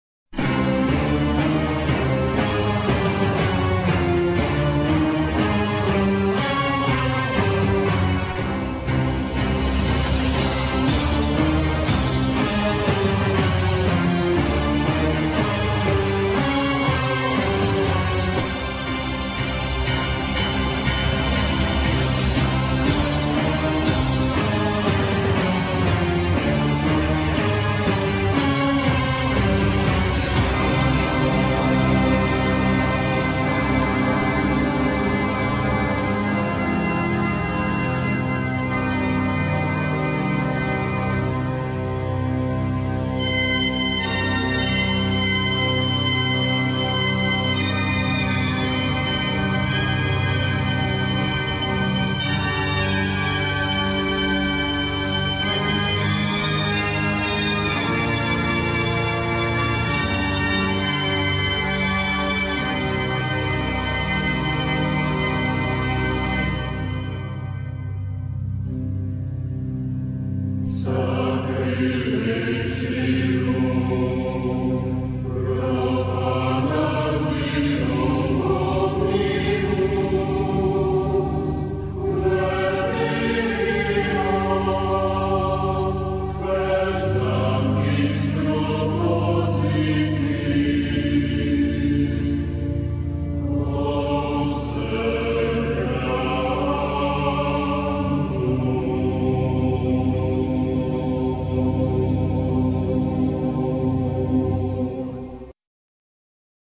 vocals
drums, percussion
guitars
piano
keyboards
violin
cello
organ
chorus